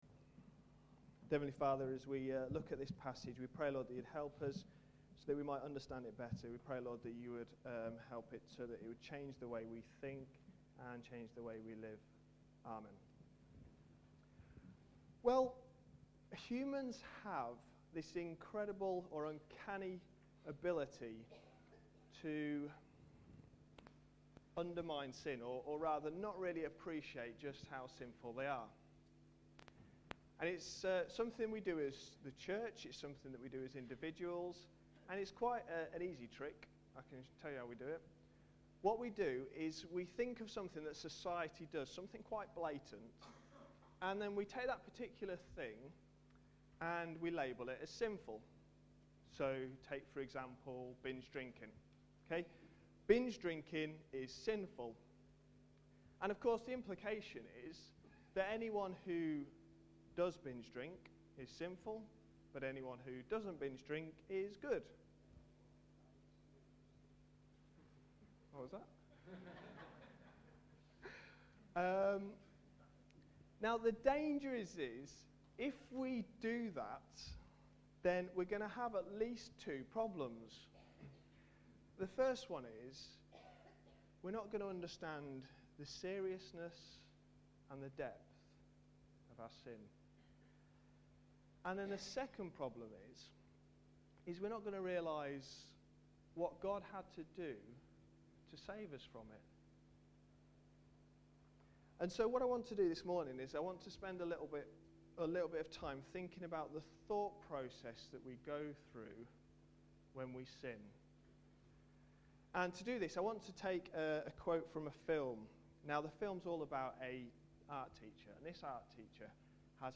A sermon preached on 23rd January, 2011, as part of our Ephesians series.